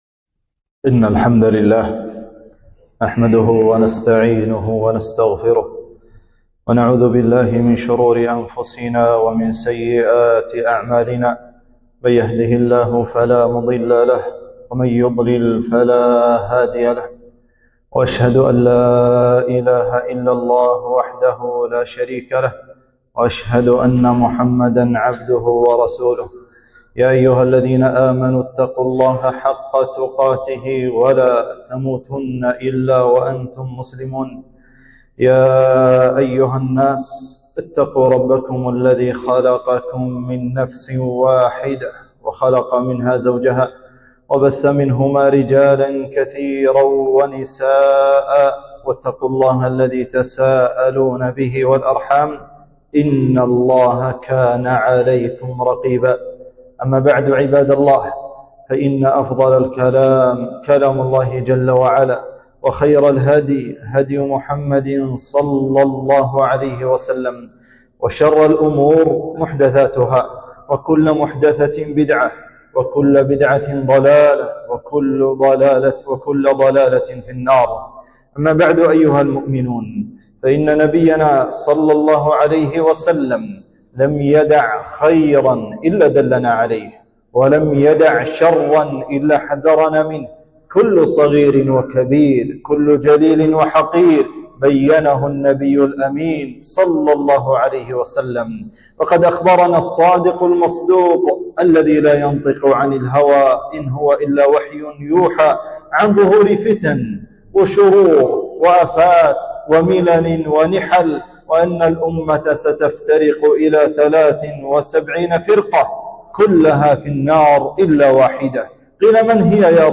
خطبة - التحذير من الخوارج